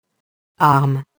arme [arm]